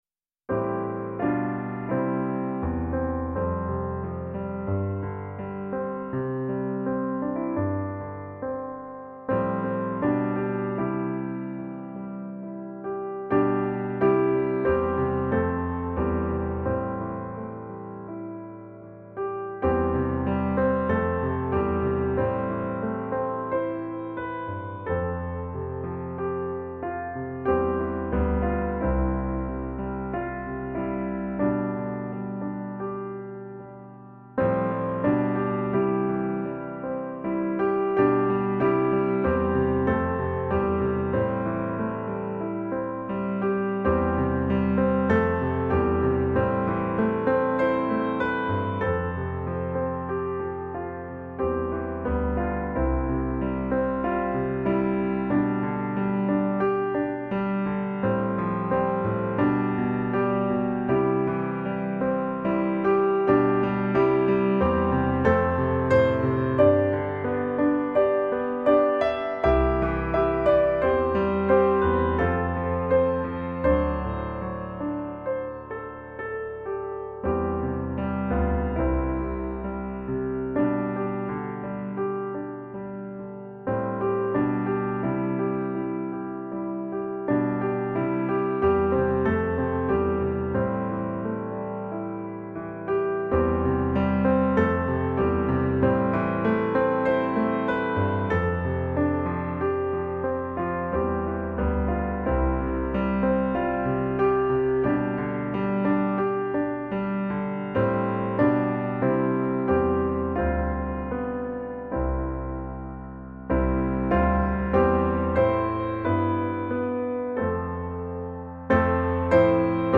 As we move into the season of Lent this coming week and ponder the mysteries of our longings and fallibility, of Jesus’ willingness to be falsely accused and unjustly executed, of his final victory over darkness and death and what that means to us…I offer this piano setting of a poem I wrote based on John Donne’s Holy Sonnet 10, known for it’s opening line “Death be not proud”.